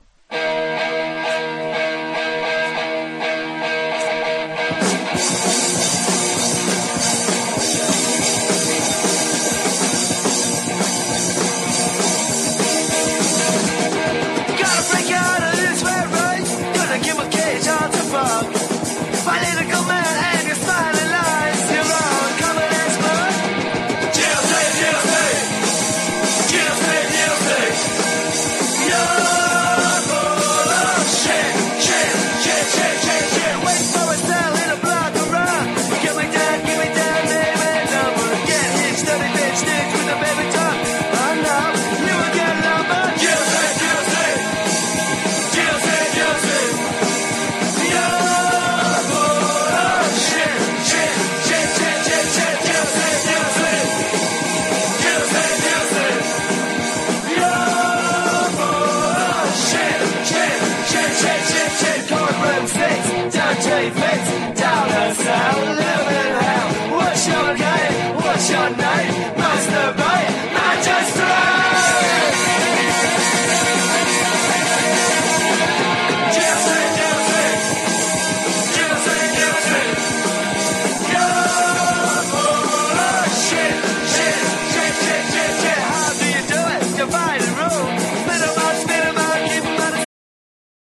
1. 70'S ROCK >